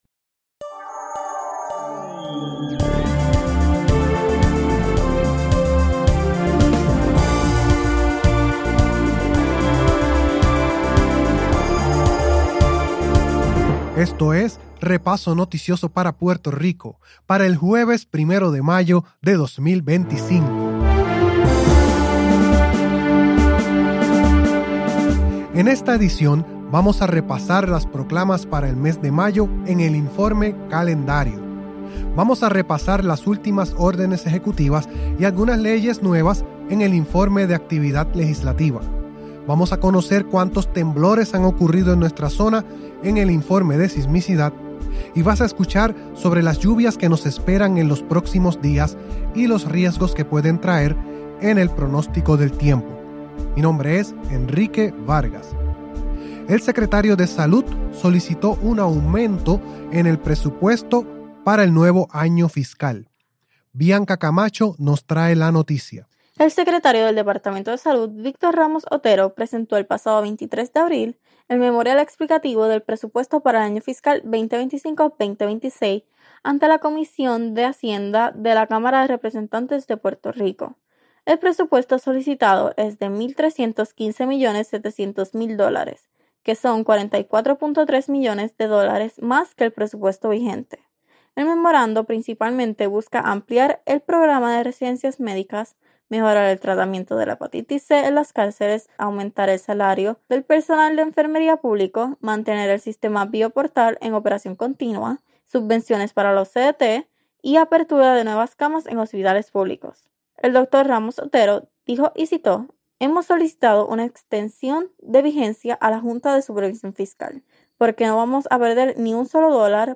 Prestamos una atención especial a los temas de aire, agua, seguridad alimentaria, albergue, demografía y desperdicios. También presentamos reportajes, especiales y crónicas de actualidad.